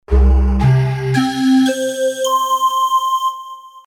Following a full 44 kHz 24 bits STEREO recording from original machines, this will be updated when new sounds (for suggestions drop me a edit LINE).
edit ST-01 SAMPLES FROM ROLAND D50 A classic digital synthesizer from 1987 based on LA synthesis, also well-known to retrogamers for the Roland MT32 module.
Call Living Calliope demo